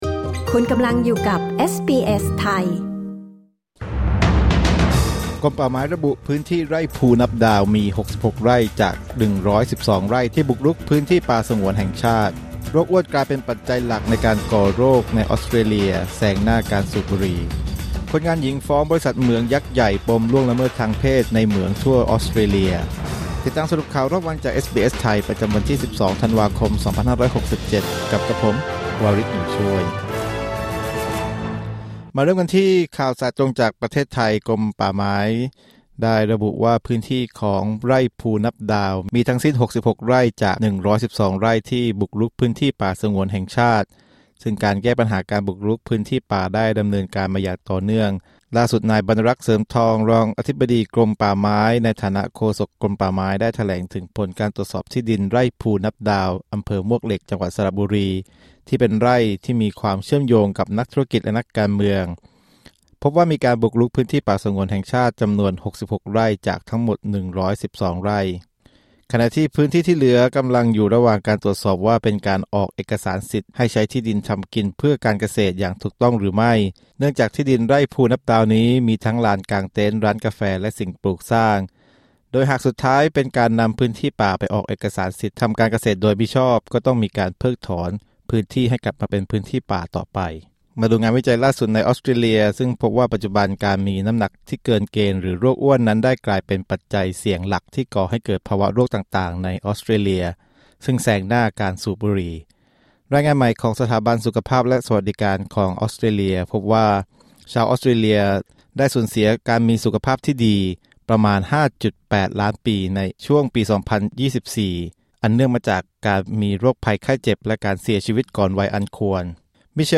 สรุปข่าวรอบวัน 12 ธันวาคม 2567